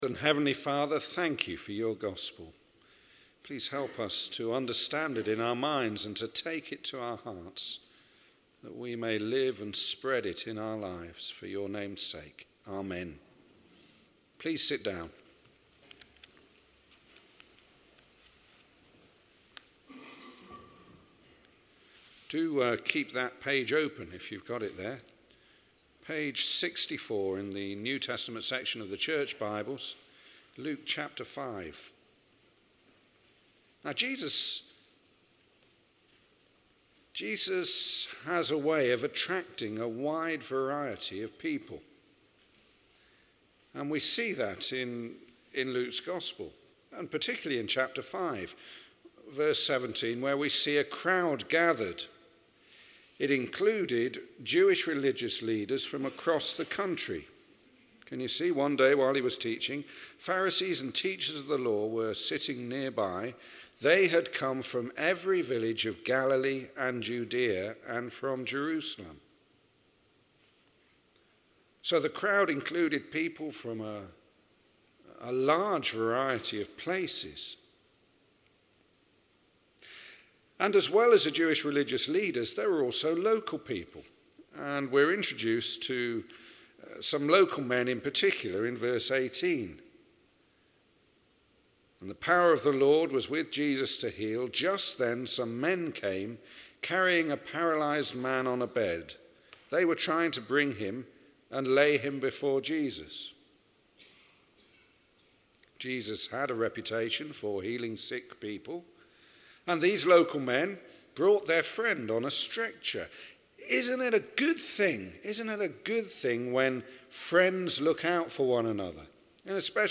Sermons 2nd March 2014: